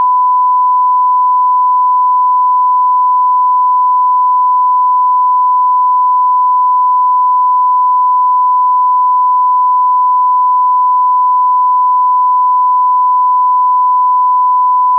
SmartAudio/11025-sine-1khz-stereo-s16_le-15s.wav at fd52e99587e8f15c28df951202b45d6693bd498a